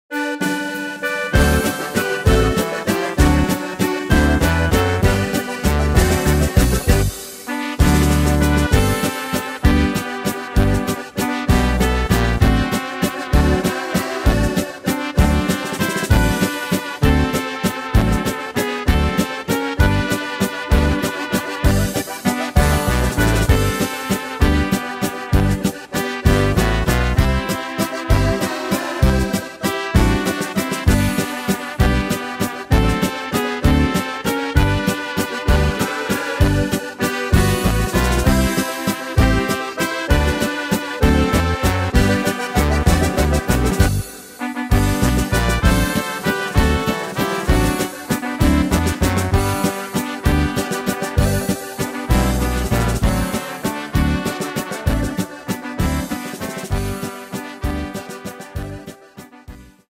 Tempo: 195 / Tonart: G-Dur